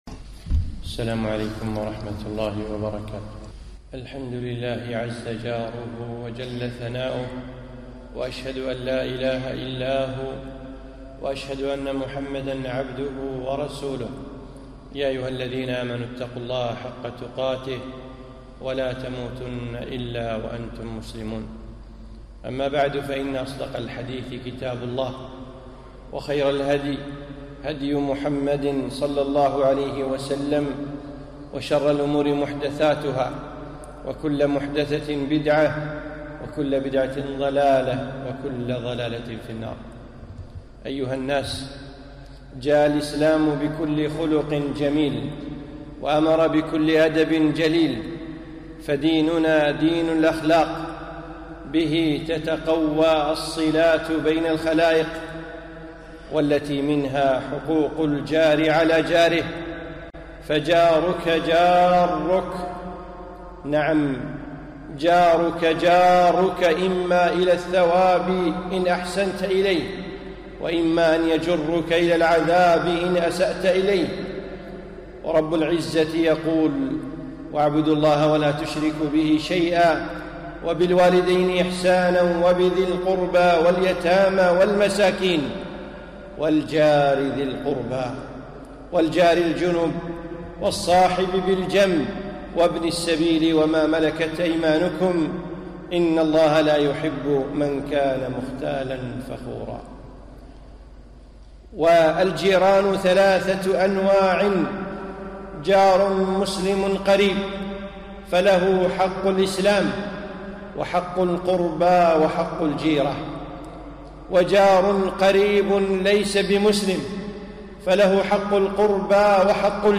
خطبة - جارُك جارُّك